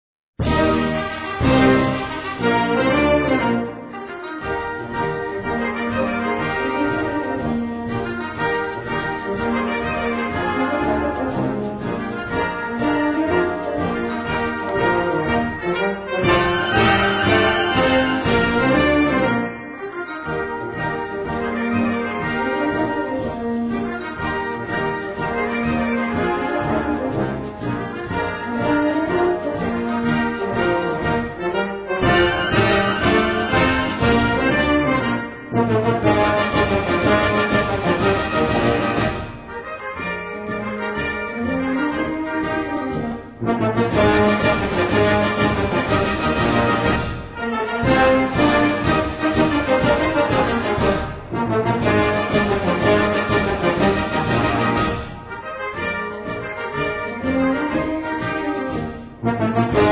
Gattung: Festmarsch
Besetzung: Blasorchester